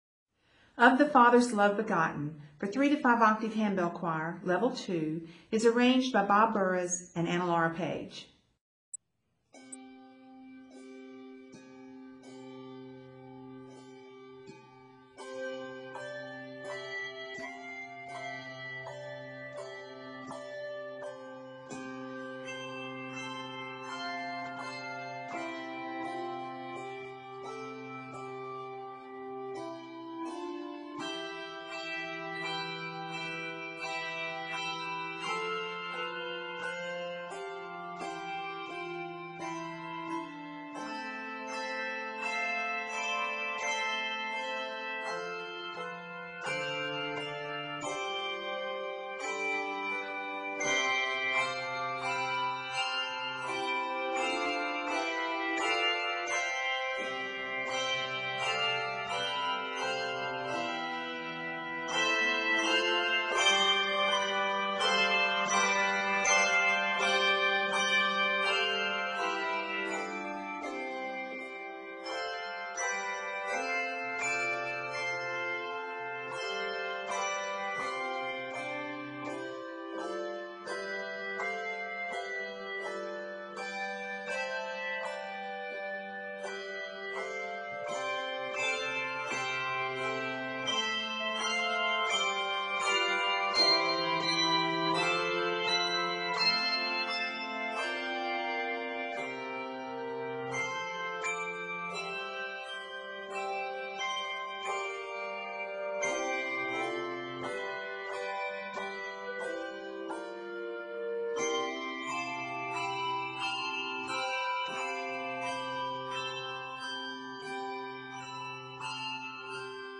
is a quiet, beautiful setting of the 13th century plainsong
arranged for 3-5 octave handbell choir.